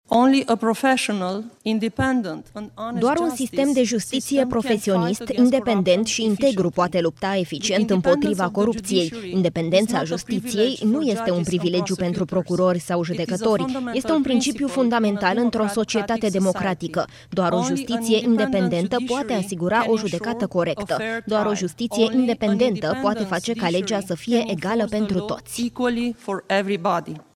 Laura Codruța Kovesi a luat cuvântul în cadrul dezbaterii prilejuite de aniversarea a 15 ani de la adoptarea Convenției Națiunilor Unite împotriva Corupției, mai cunoscută drept Convenția de la Merida: